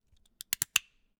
Part_Assembly_32.wav